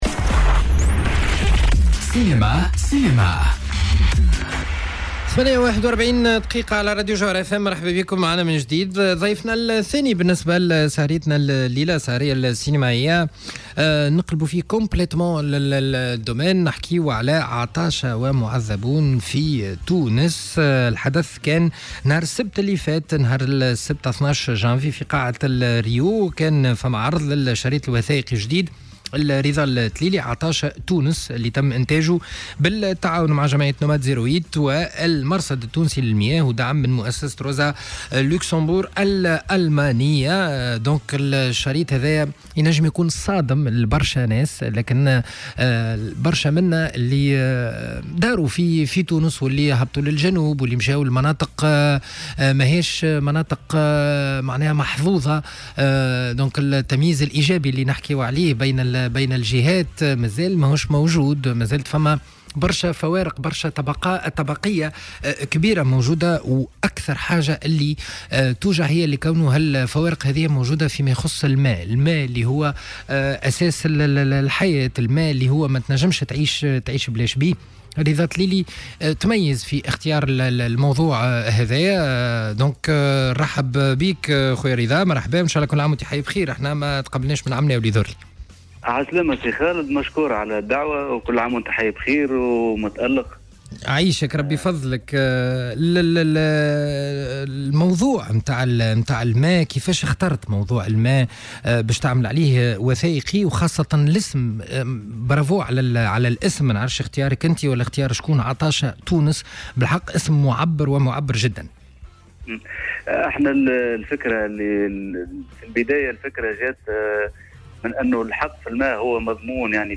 استضافته في برنامج "سينما سينما" على الجوهرة أف أم